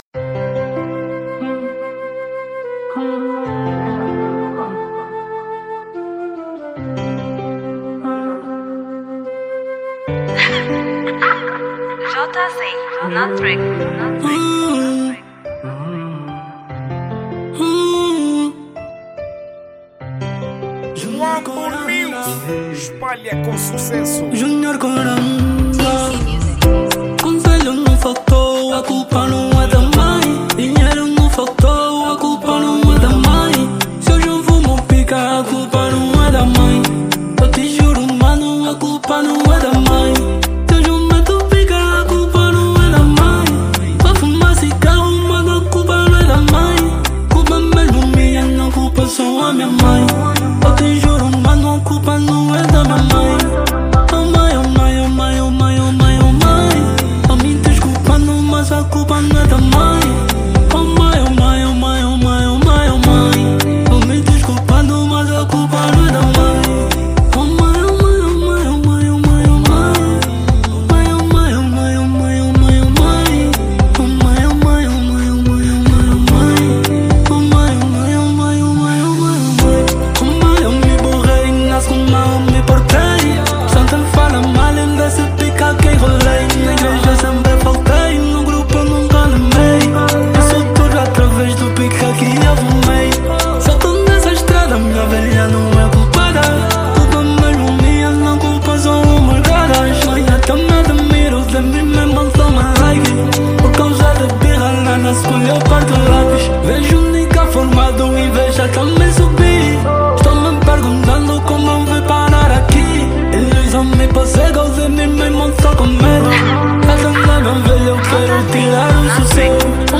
| Kuduro